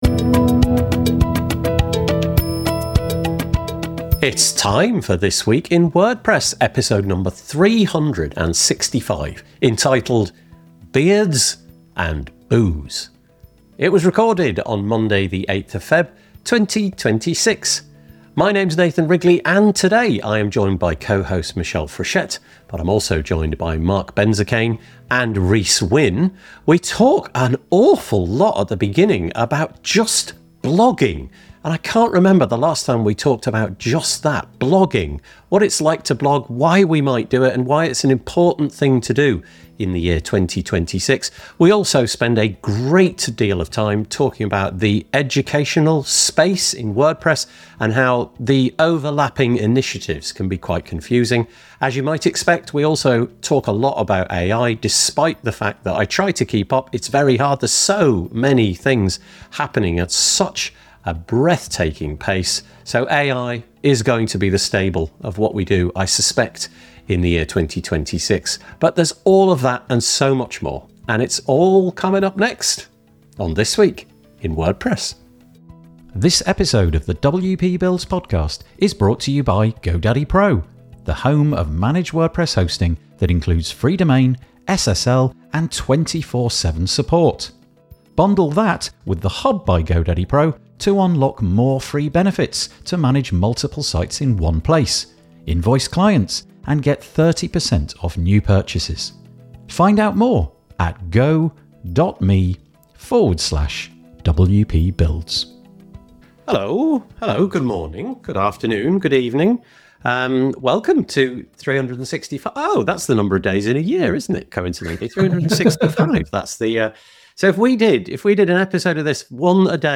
Panelists debate the ethics, utility, and future impacts of AI, the challenges of local meetups, and celebrate community efforts. The show features lively listener interaction and concludes with reflections on blogging versus social media, and ongoing WordPress community changes.